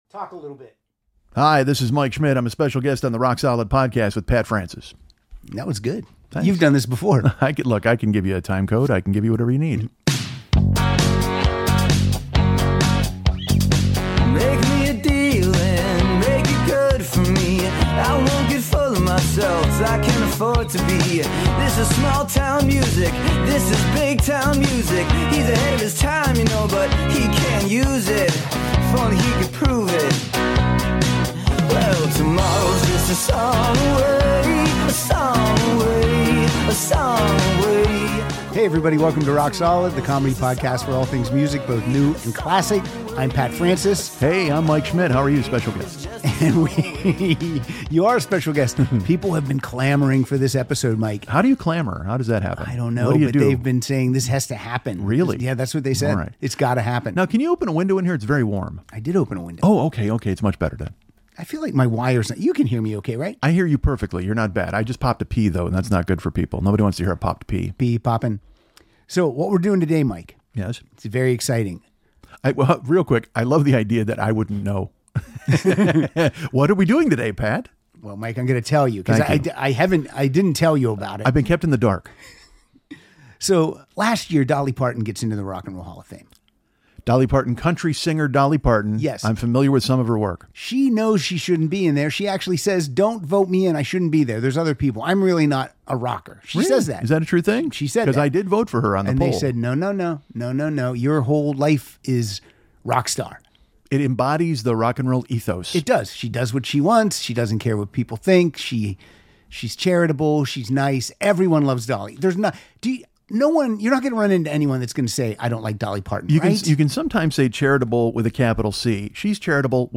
track by track commentary